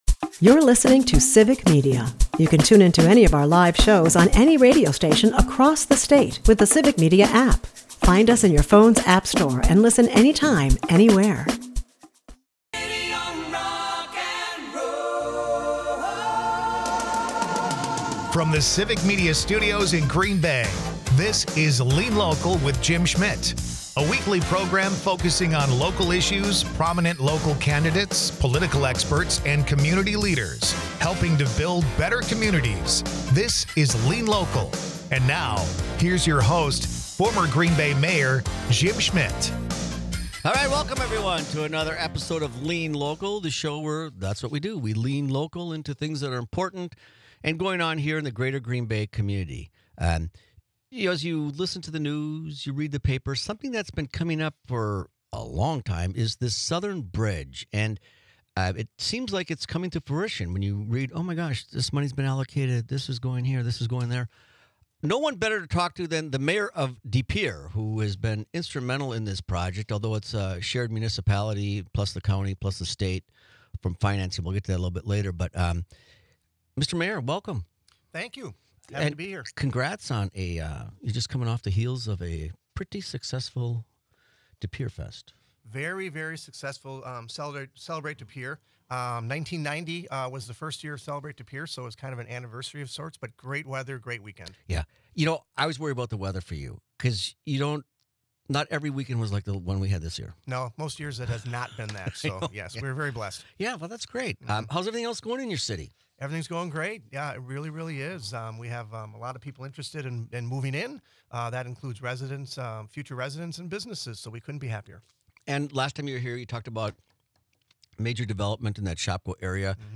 Former Green Bay Mayor Jim Schmitt is joined by De Pere Mayor, James Boyd. James and Jim talk about the South Bridge Connector in De Pere, after 60 years it is officially underway.
Then Highway Commissioner, Chris Hardy joins the conversation.